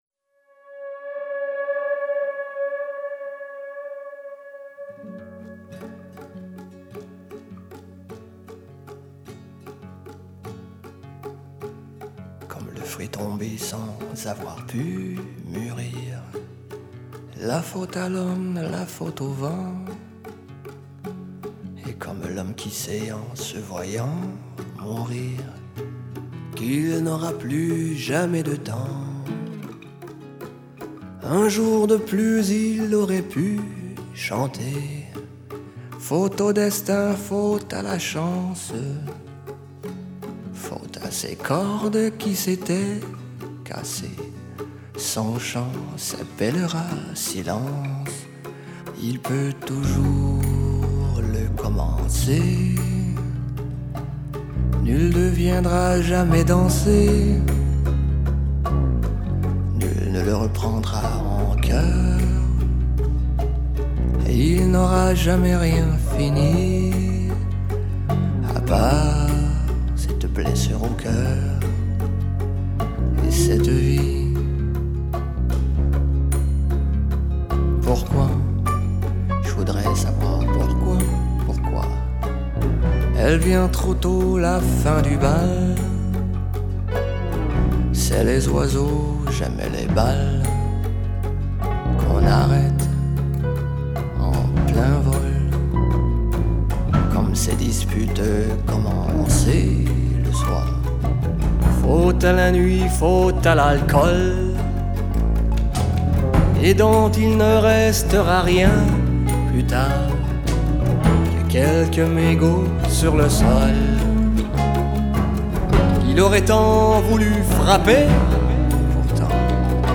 контрабас
аккордеон и скрипка
фортепиано